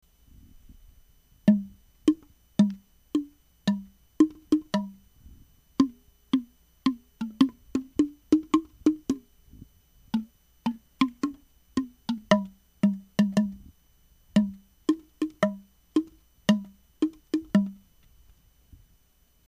どんべぇ太鼓　＜１９９９年＞
どこまで水に浸けるかで、音の高さが変わります。
試聴　容器を5個使って演奏しています。